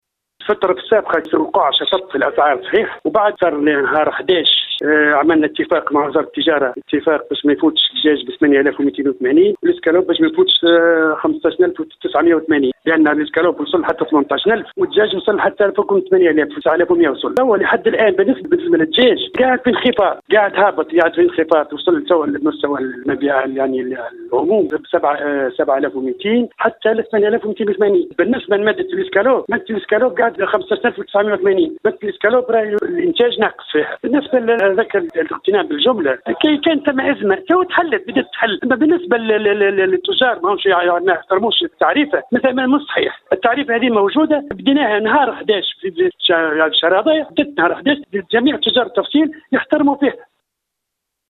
تصريح للجوهرة أف أم